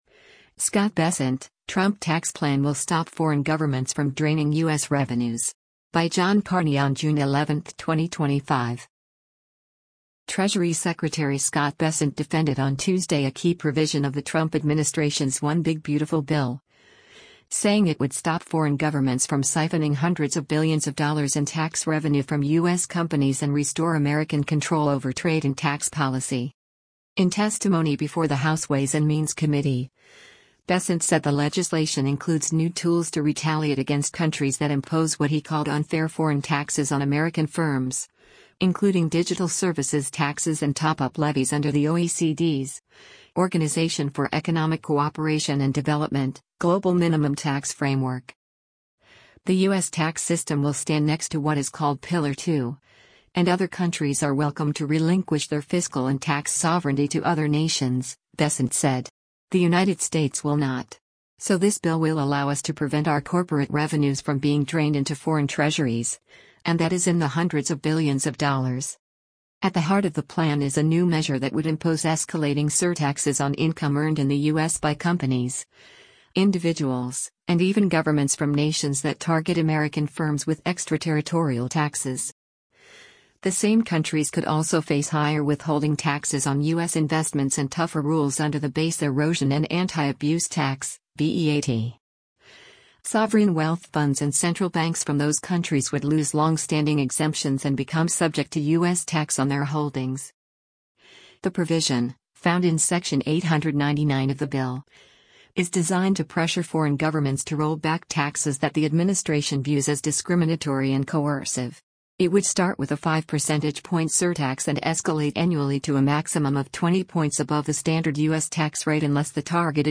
Treasury Secretary Scott Bessent testifies before the House Ways and Means Committee heari